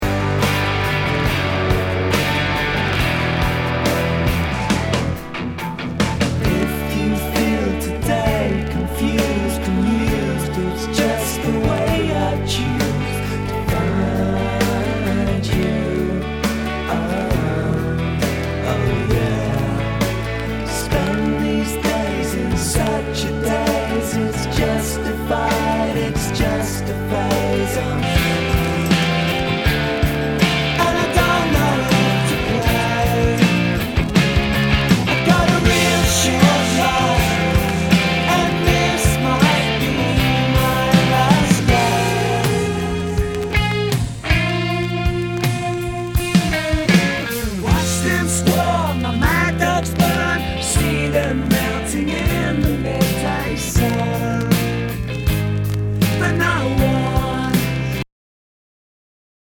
[ Genre ] ROCK/POPS/INDIE